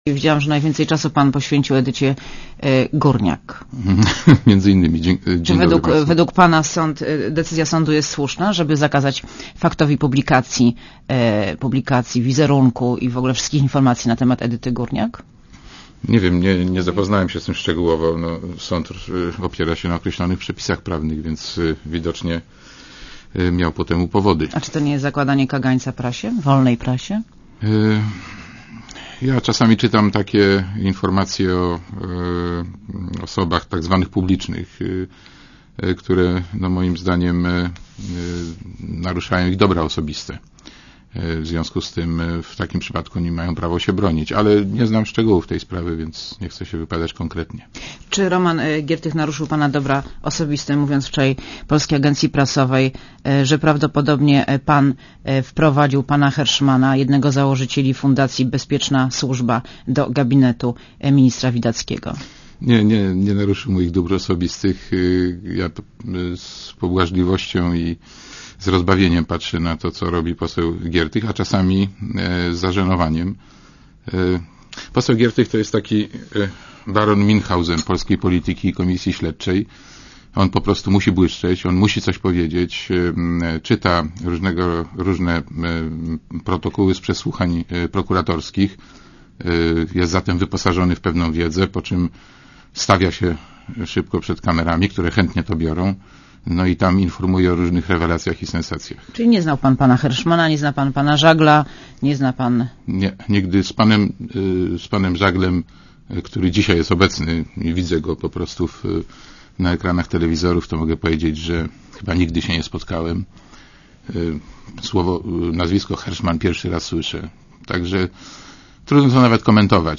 gosc_borowski.mp3